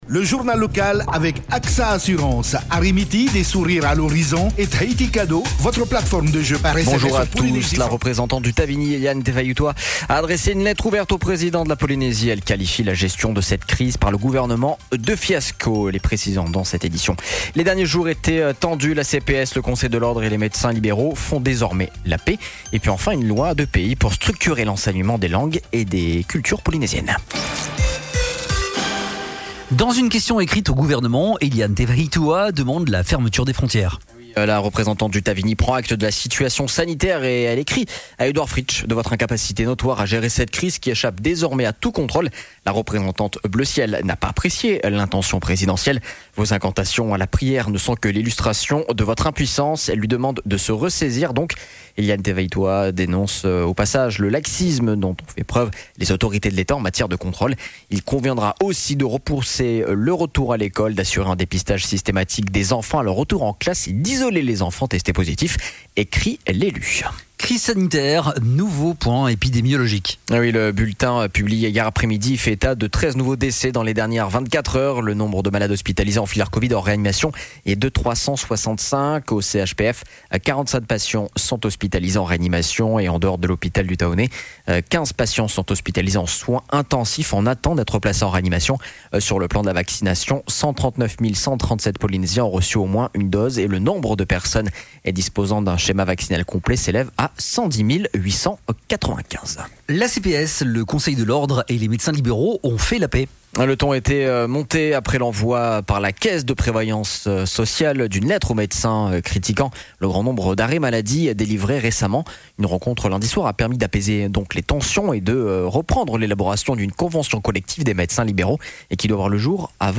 Journal de 7h30, le 01/09/21